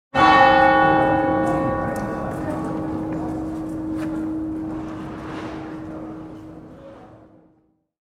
Church Bell Strike Sound Effect – Town Square Bell
This church bell strike sound effect captures the deep, resonant tone of a bell ringing in a town square with lively city ambience, including people, crowd chatter, street activity, and urban background sounds. Perfect for videos, films, apps, games, or cinematic projects, this authentic church bell and town sound delivers festive, dramatic, historical, and bustling city atmosphere.
Church-bell-strike-sound-effect-town-square-bell.mp3